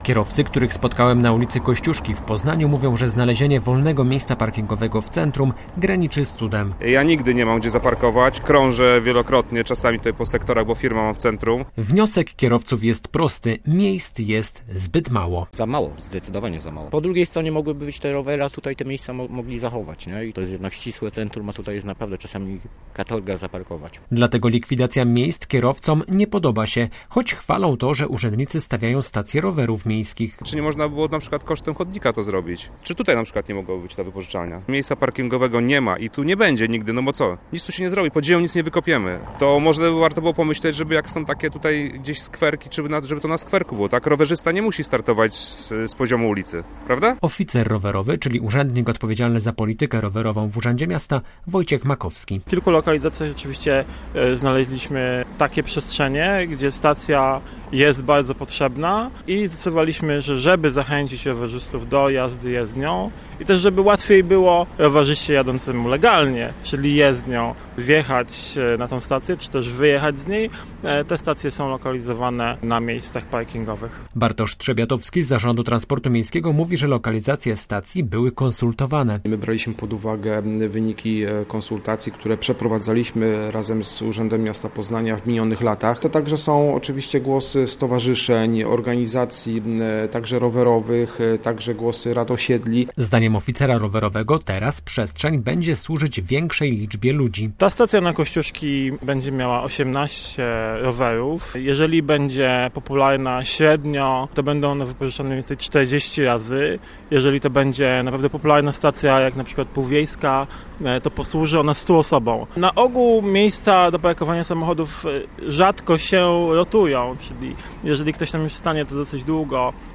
- Rowery też są potrzebne, ale nie kosztem kierowców. W centrum nigdzie i nigdy nie można zaparkować. Krążymy w poszukiwania miejsc. Czy nie można było parkingów dla rowerów wydzielić kosztem chodnika - komentują kierowcy w rozmowie z reporterem Radia Merkury.